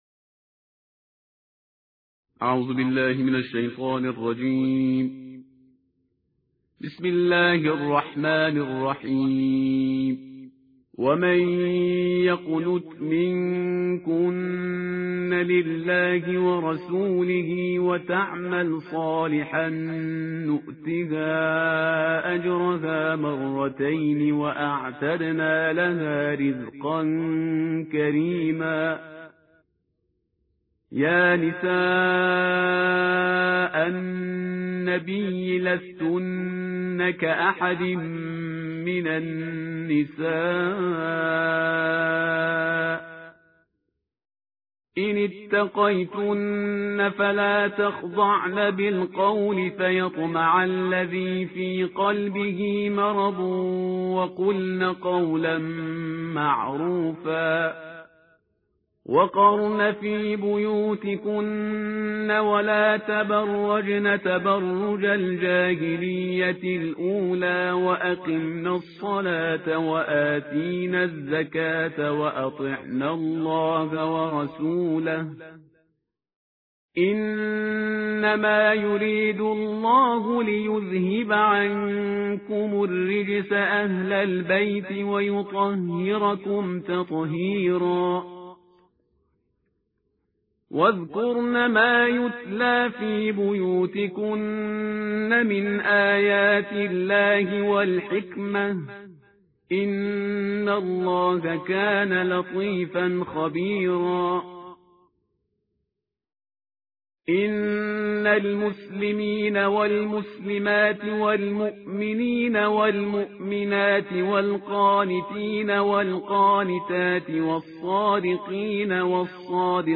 ترتیل جزءبیست و دوم قرآن کریم